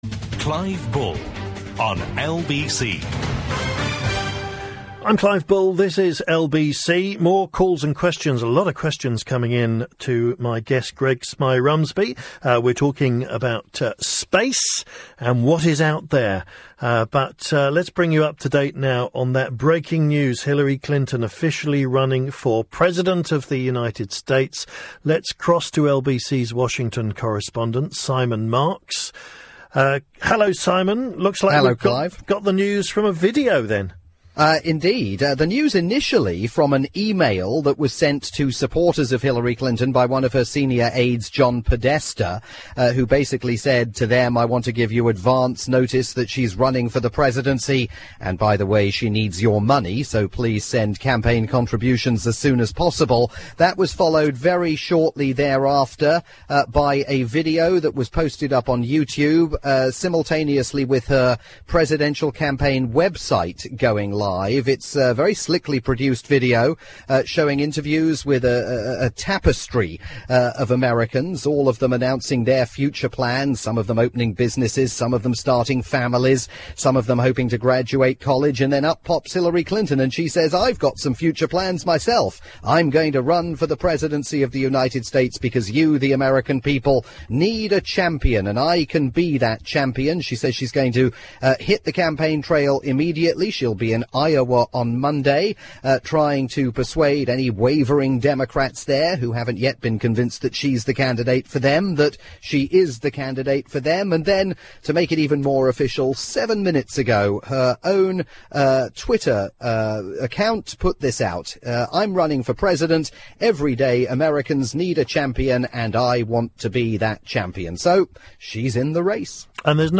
live update